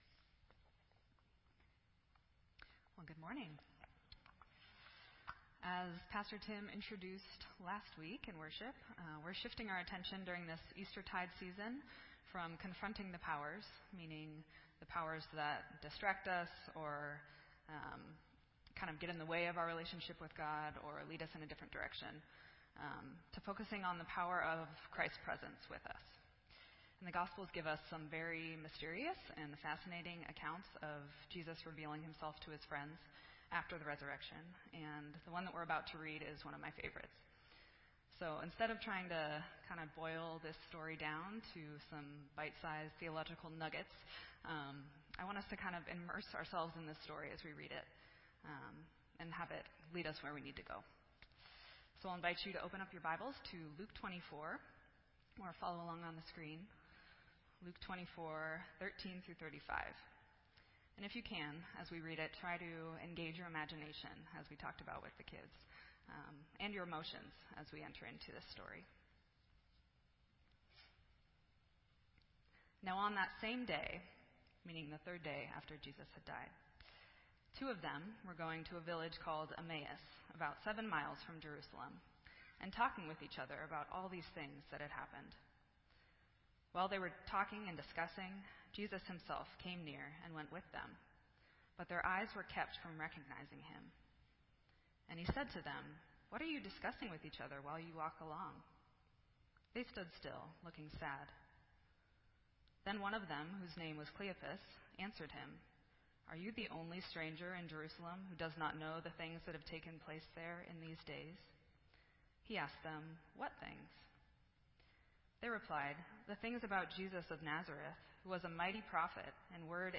This entry was posted in Sermon Audio on May 1